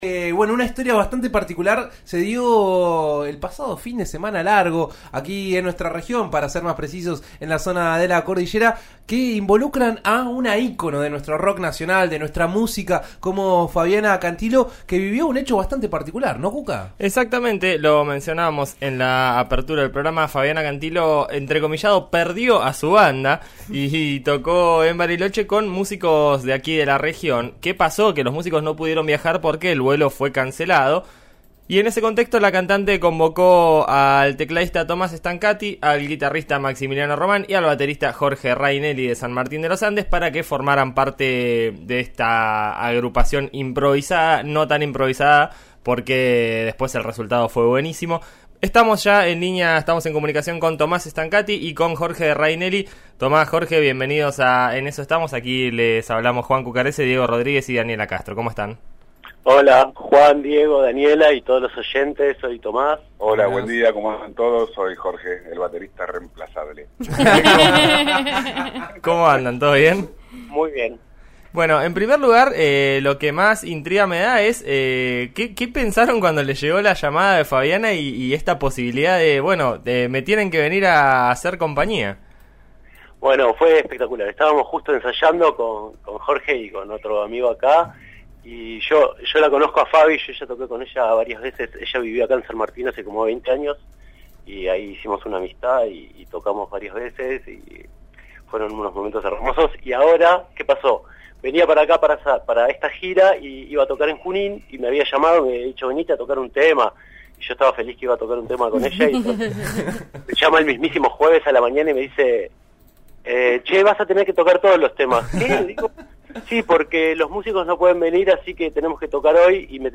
En diálogo con En eso estamos de RN Radio (90.9 en Neuquén y 105.7 en Roca) los músicos contaron cómo fue vivir un fin de semana único y diferente. Desde el primer llamado de Fabiana donde los convocaba a tocar con ella, hasta los tres espectáculos que deslumbraron al público.